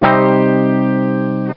Guitar Min Sound Effect
Download a high-quality guitar min sound effect.
guitar-min.mp3